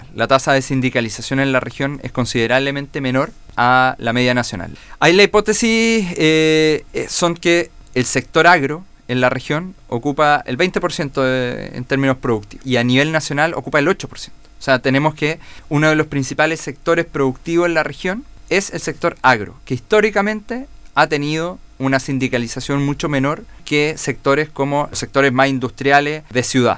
En entrevista con Radio Bío Bío de Valdivia, el director nacional de esta entidad de Gobierno, Sergio Santibáñez, dio a conocer que esta región se destaca por tener una estabilidad laboral sobre el promedio país, ya que el 83% de trabajadores dependientes cuenta con contrato indefinido y el 44% de ellos lleva más de 3 años en esta condición.